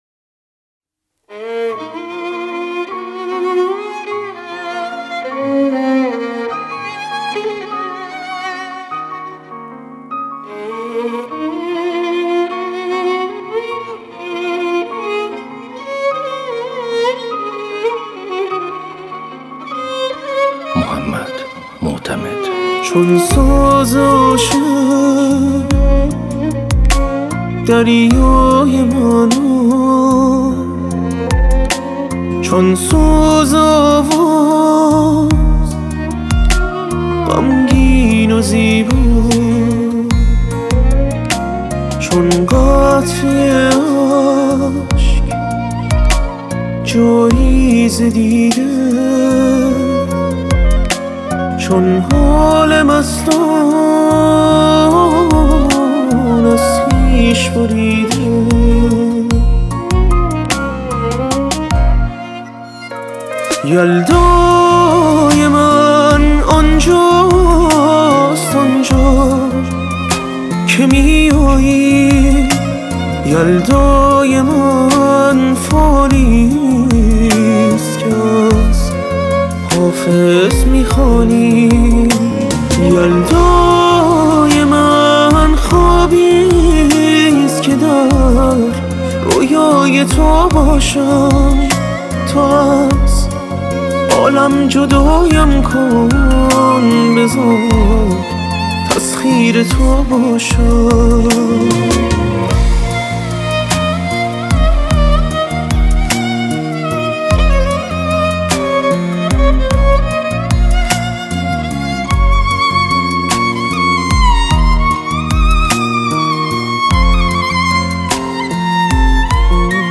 تنظیم و گیتار باس
ویولون